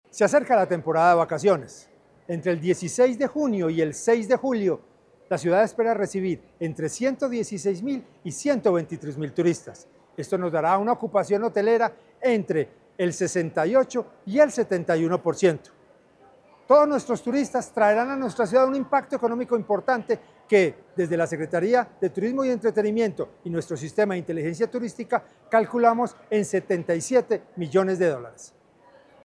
Declaraciones secretario de Turismo y Entretenimiento, José Alejandro González En su consolidación como destino para el turismo de reuniones, salud, deportivo y de ocio, la ciudad espera recibir entre 116.000 y 123.000 pasajeros en las vacaciones de mitad de año.
Declaraciones-secretario-de-Turismo-y-Entretenimiento-Jose-Alejandro-Gonzalez.mp3